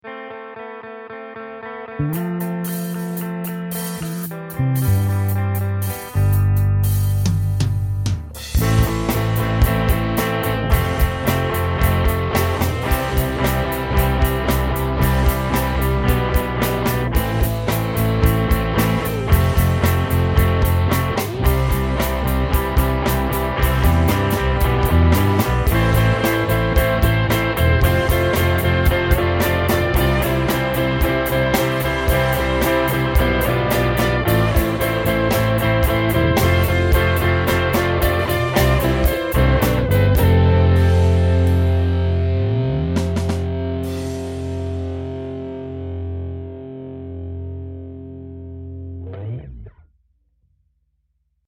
4\4 rock simple drum beat